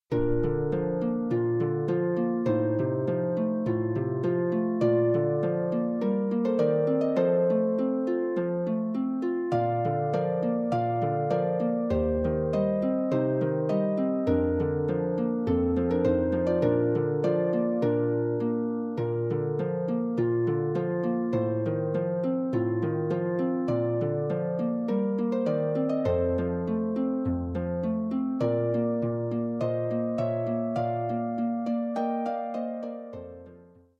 arranged for solo pedal harp.